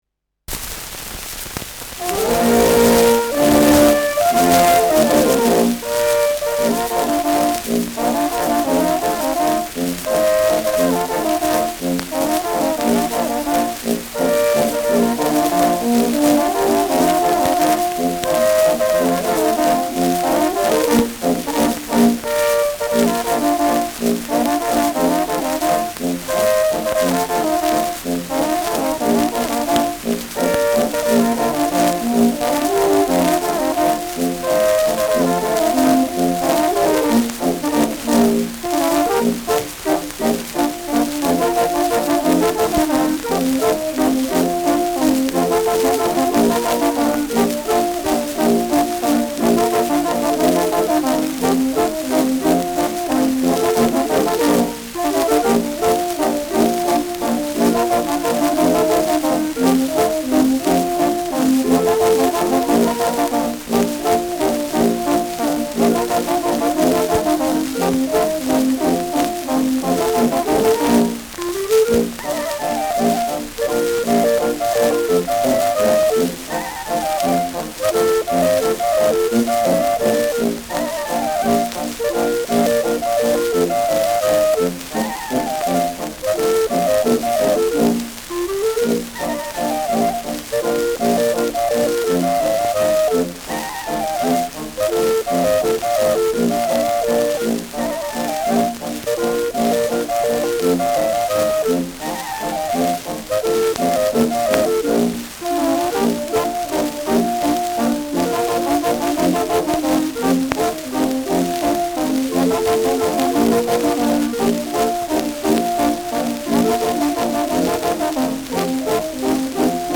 Schellackplatte
präsentes Rauschen : abgespielt : leichtes Leiern : gelegentliches Knacken : gelegentliches Nadelgeräusch
Kapelle Peuppus, München (Interpretation)